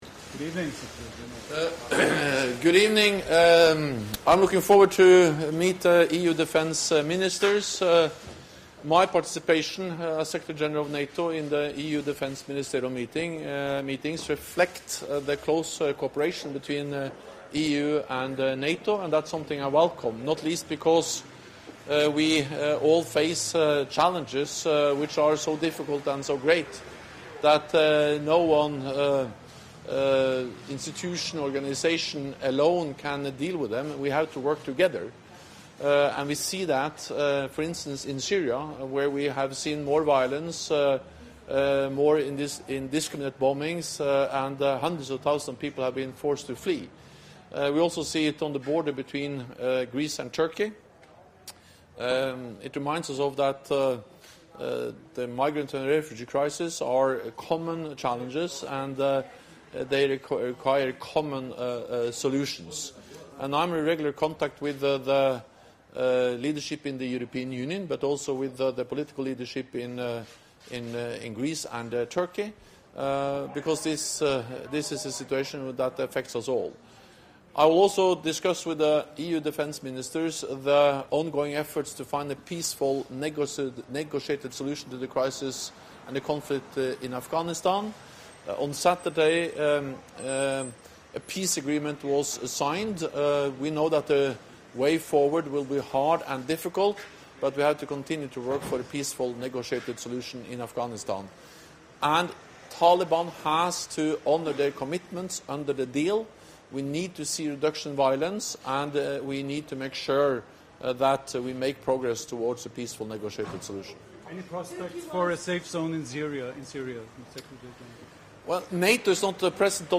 Doorstep statement
by NATO Secretary General Jens Stoltenberg ahead of a working dinner with EU Defence Ministers (Zagreb, Croatia)